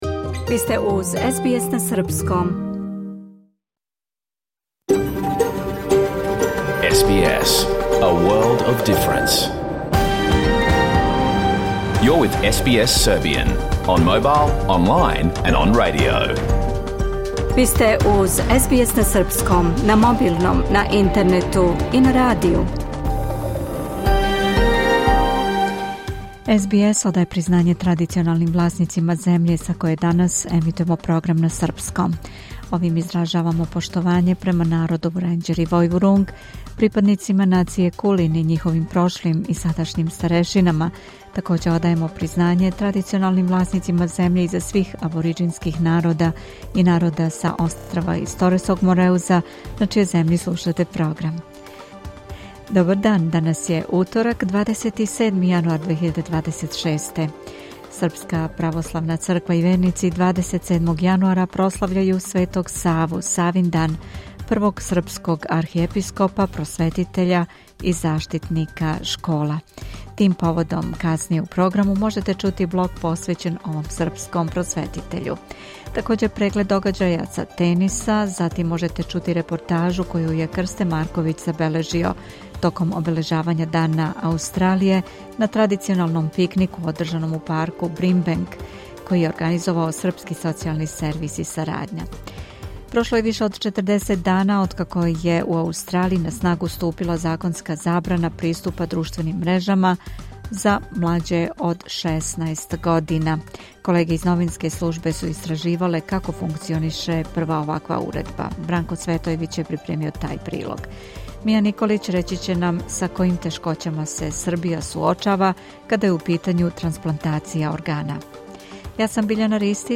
Програм емитован уживо 27. јануара 2026. године